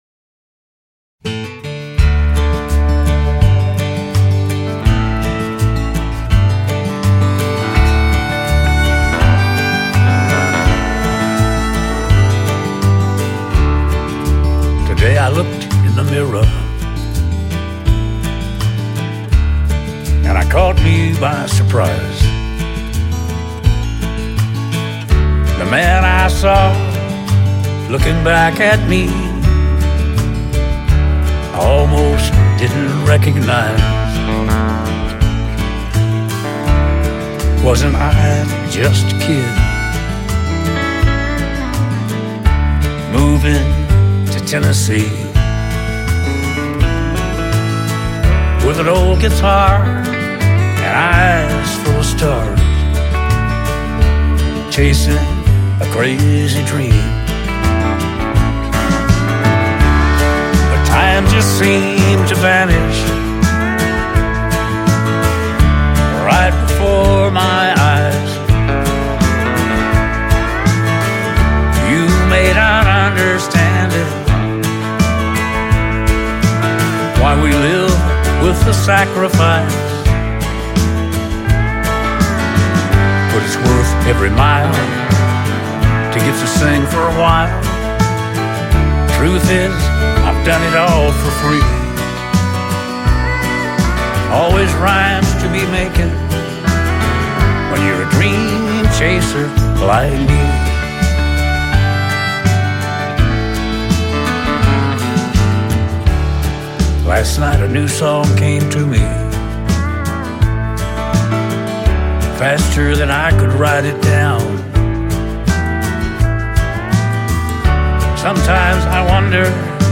A reflective and heartfelt song
showcasing his signature country style and storytelling.